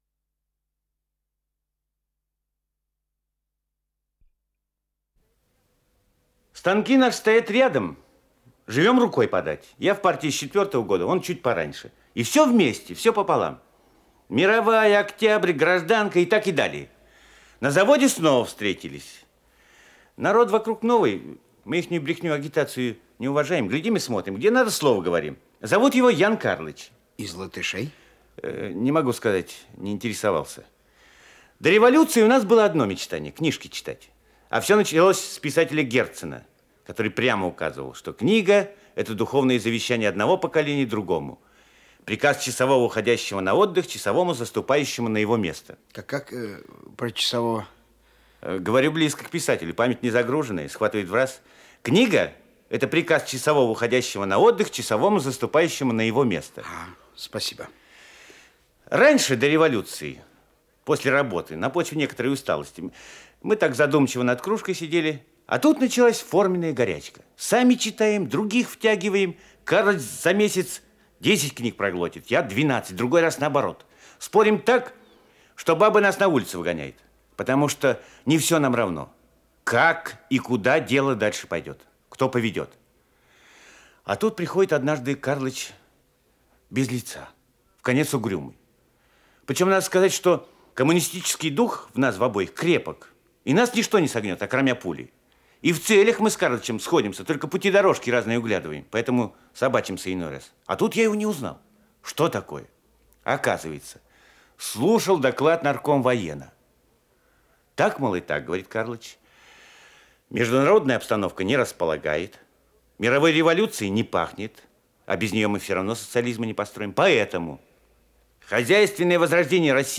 Исполнитель: Артисты МХАТа СССР им. Горького
Спектакль МХАТ СССР им. Горького